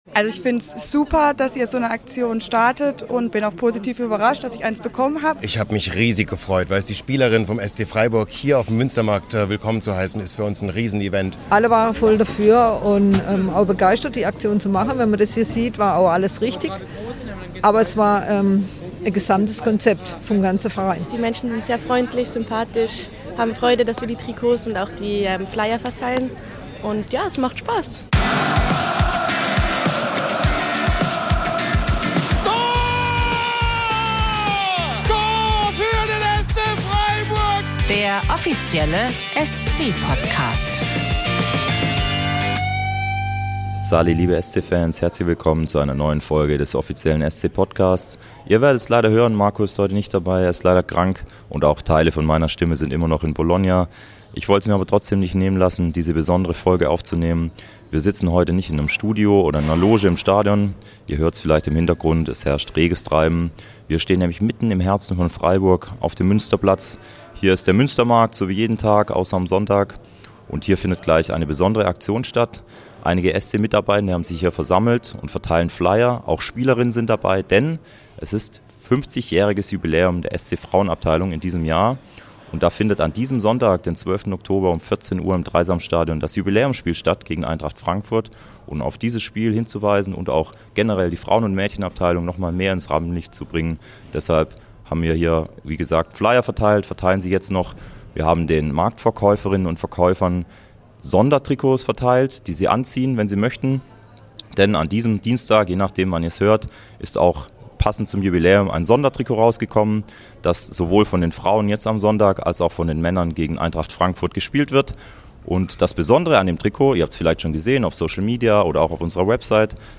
In dieser Podcastfolge nehmen wir euch mit auf den Münstermarkt. Die SC-Frauen feiern 50-jähriges Jubiläum und haben mit einer ganz besonderen Aktion im Herzen Freiburgs auf ihr Jubiläumsspiel gegen Eintracht Frankfurt aufmerksam gemacht.
Wir haben für euch das Mikrofon mitlaufen lassen.